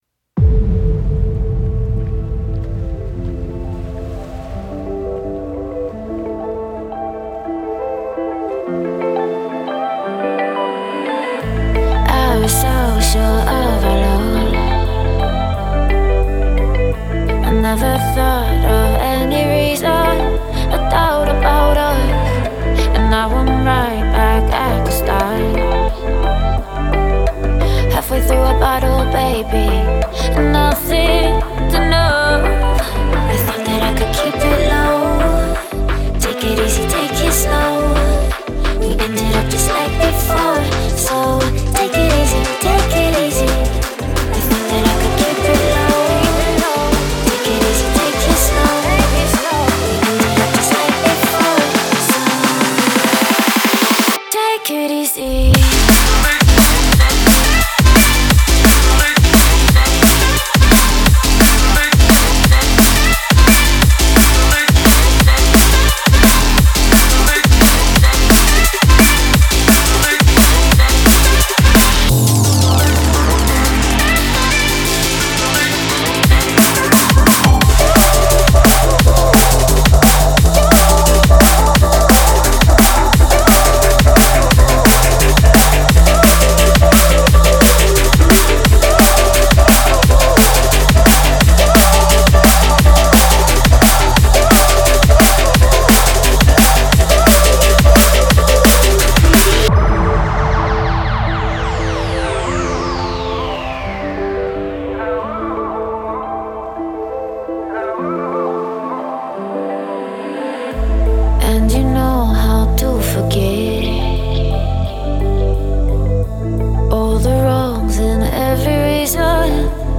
Drum & bass, Suspense, Chasing, Funny & Floating